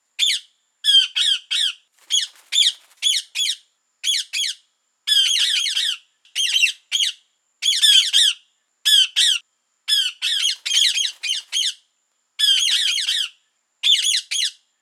Halsbansittich Geräusche
• Ihre Rufe sind laut und kreischend, oft früh am Morgen zu hören.
Halsbandsittich-Geraeusche-Voegle-in-Europa.wav